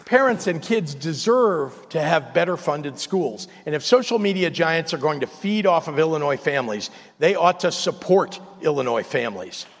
The governor delivered his State of the State and budget address at the Illinois Capitol on Wednesday.